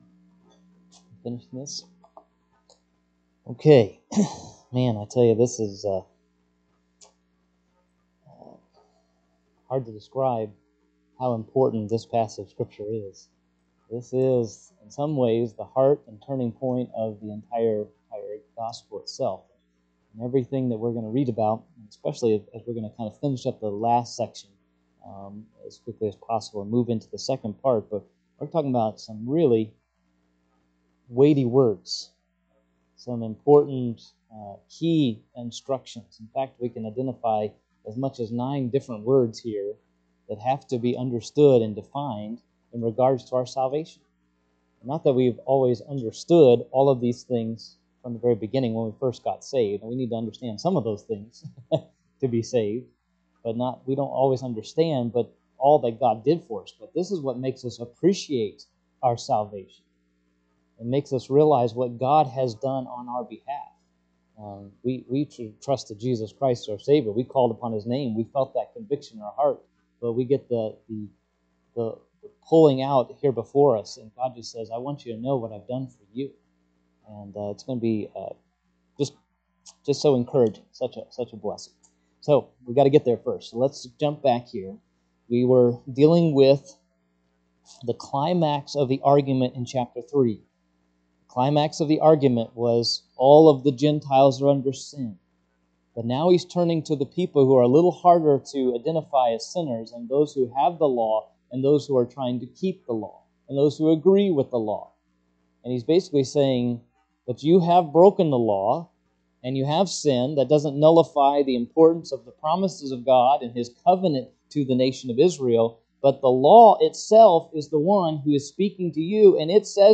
Sermons Sort By Date